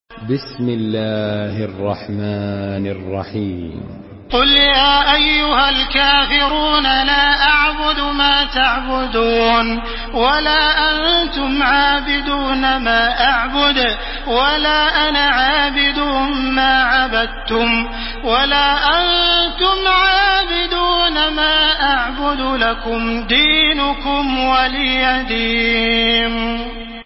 تحميل سورة الكافرون بصوت تراويح الحرم المكي 1431
مرتل